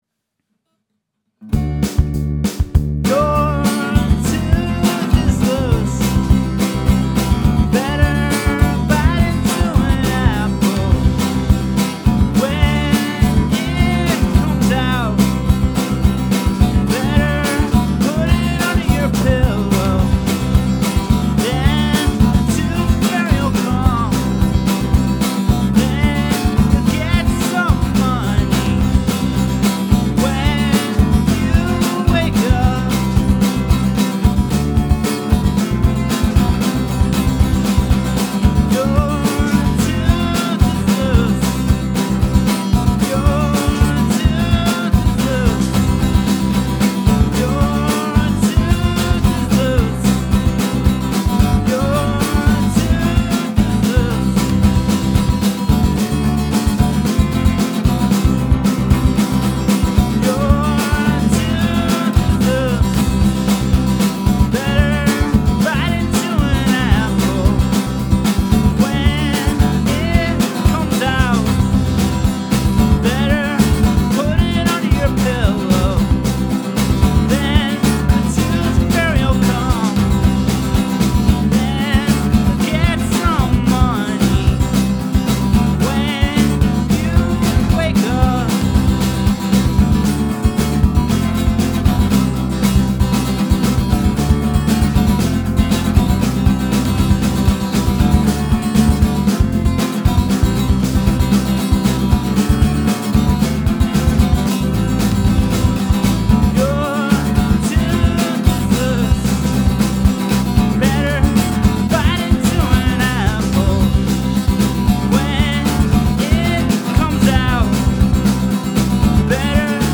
(D, F, C)